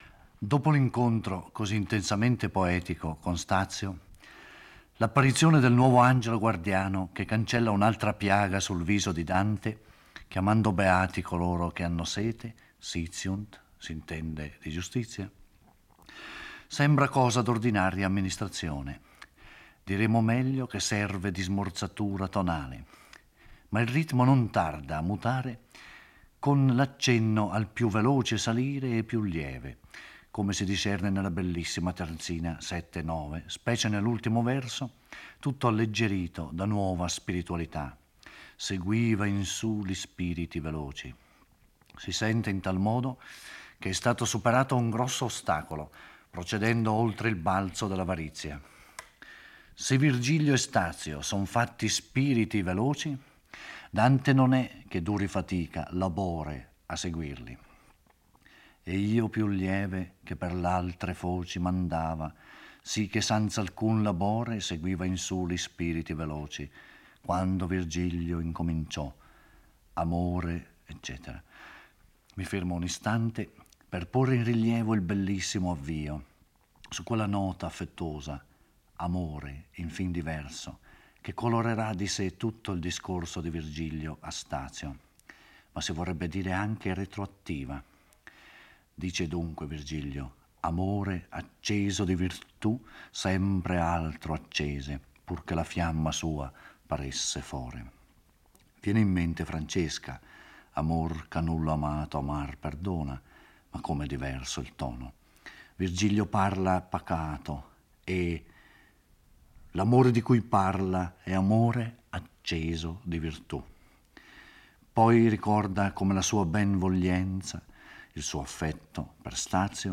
Giorgio Orelli legge e commenta il XXII canto del Purgatorio. Dopo che l'angelo ha proclamato beati coloro che hanno sete di giustizia e dopo che ha cancellato un'altra "P" dalla fronte di Dante, si giunge alla sesta cornice: qui si ergono due strani alberi a forma di abete rovesciato, dai frutti dolci e profumati, mentre dalla parete di roccia sgorga un'acqua limpida che si spande sulle foglie.